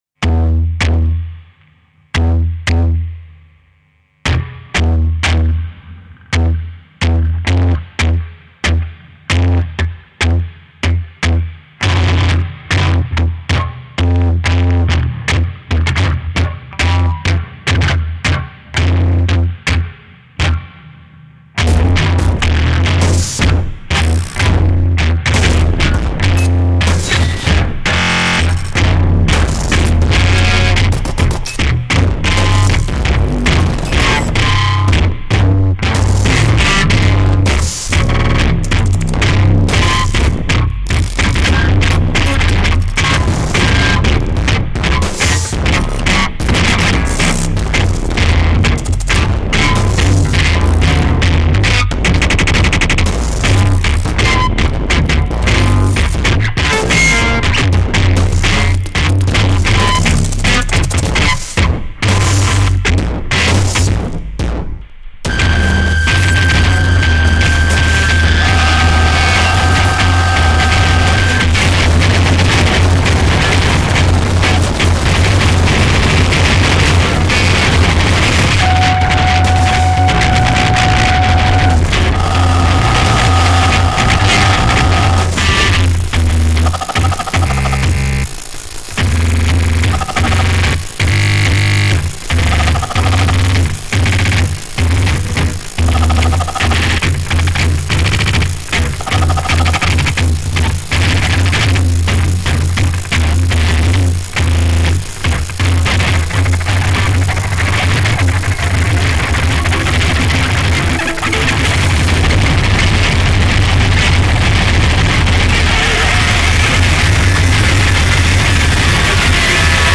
guitar, prepared guitar
analog synth, electronics, processed tapes
at his home studio in Naples, Italy